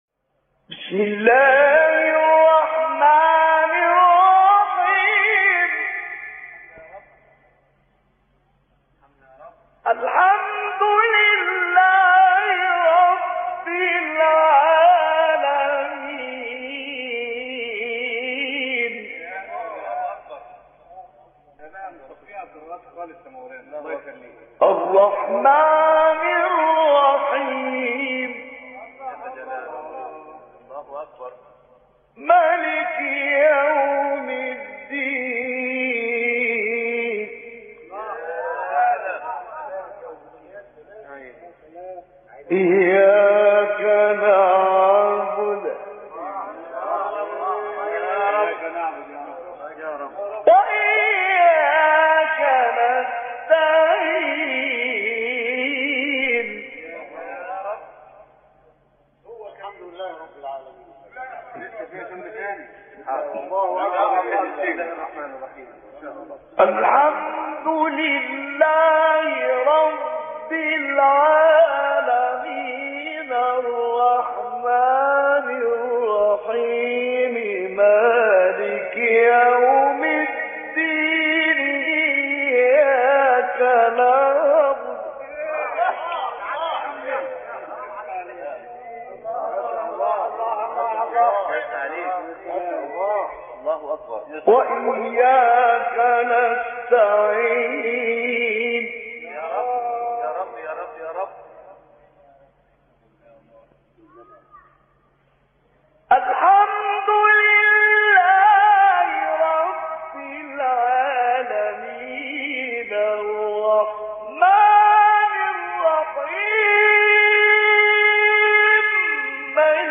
تلاوت های شاخص و گوش نواز از سوره حمد با صدای خوش خوان ترین قاریان جهان اسلام